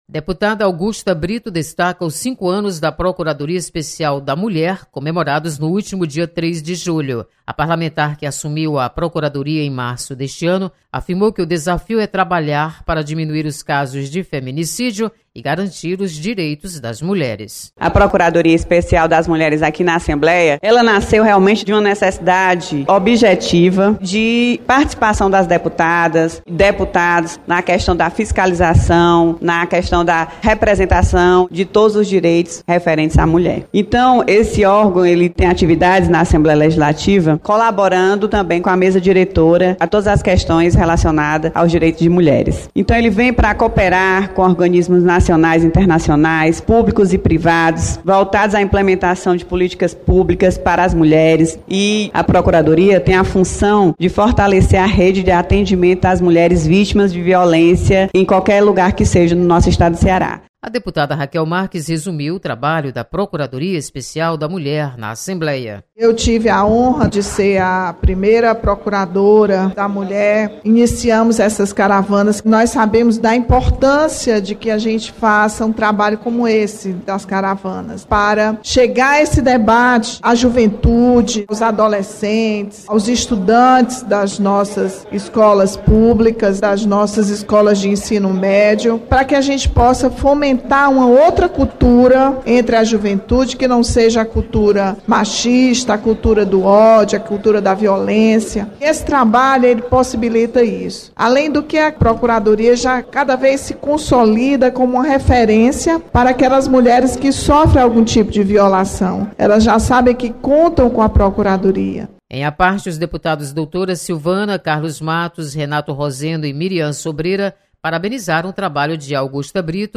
Deputadas destacam atuação da Procuradoria Especial da Mulher. Repórter